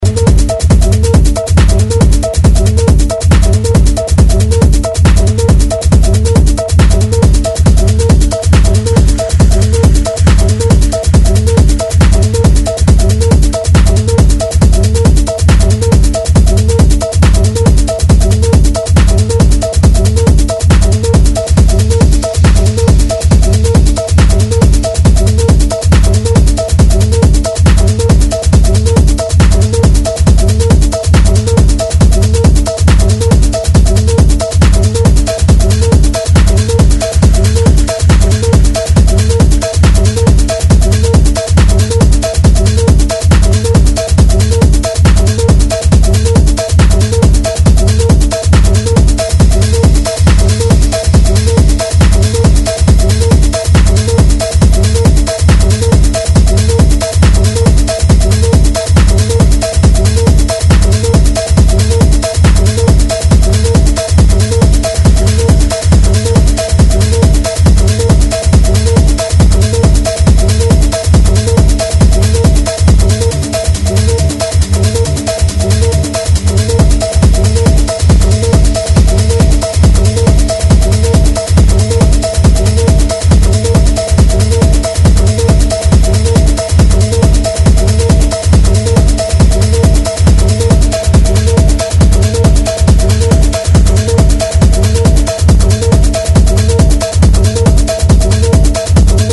Electronic
Techno